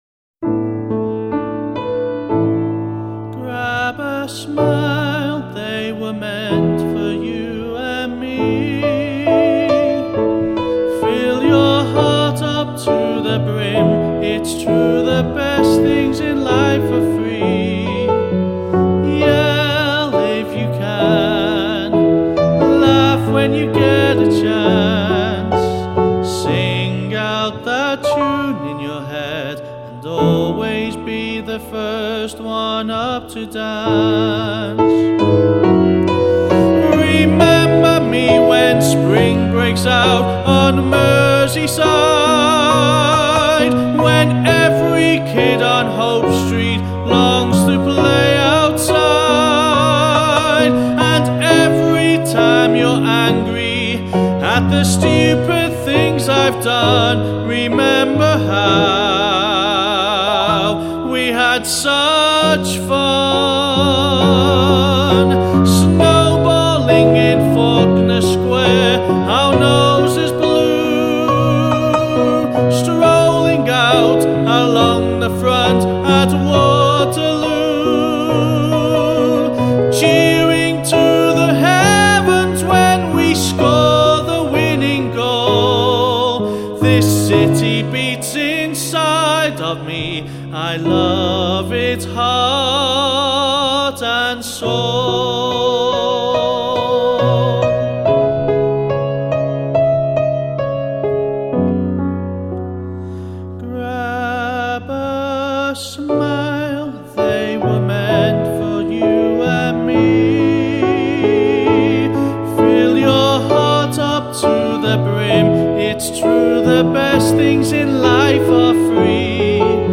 Band Call